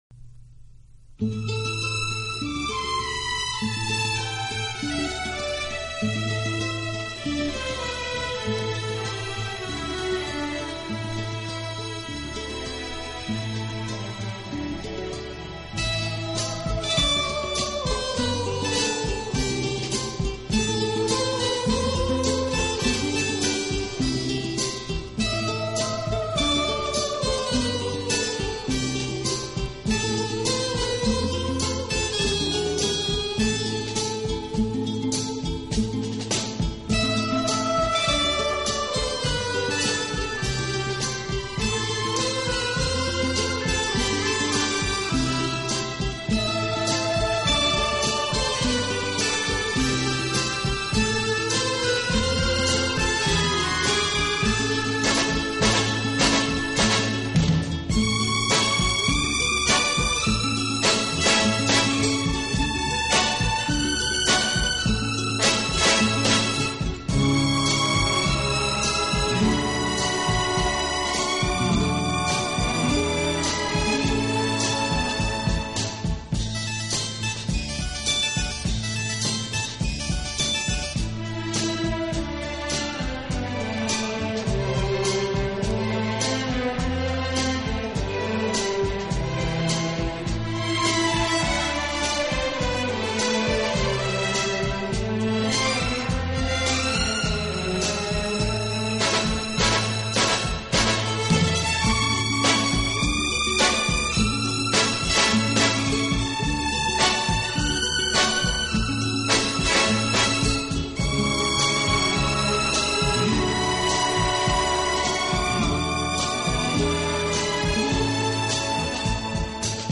乐队以弦乐为中坚，演奏时音乐的处理细腻流畅，恰似一叶轻舟，随波荡